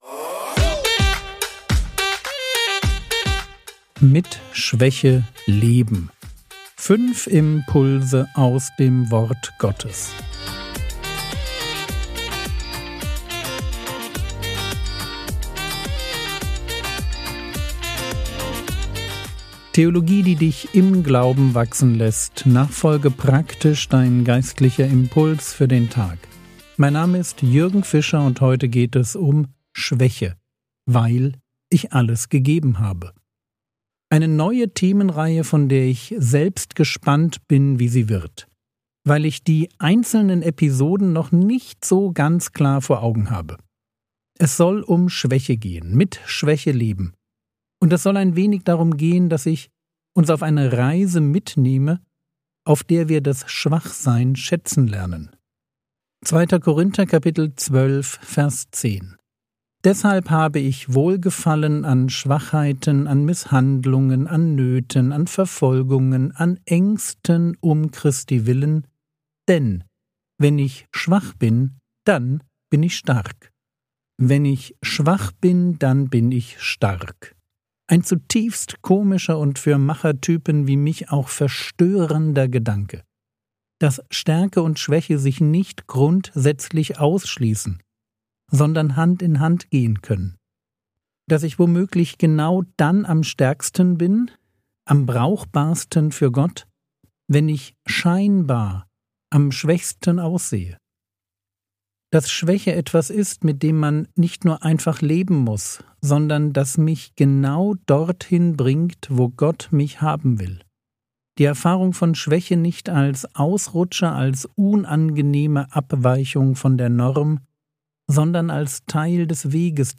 Mit Schwäche leben (1/5) ~ Frogwords Mini-Predigt Podcast